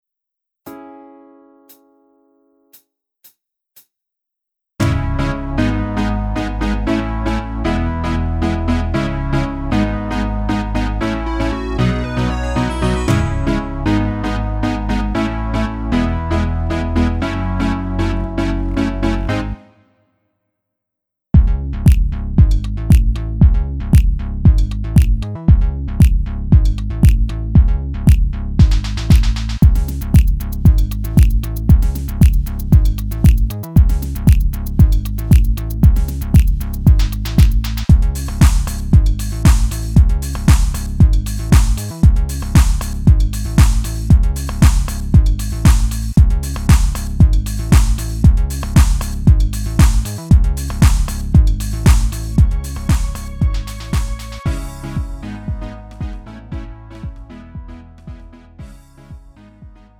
축가 및 결혼식에 최적화된 고품질 MR을 제공합니다!
음정 -1키
장르 가요 구분 Lite MR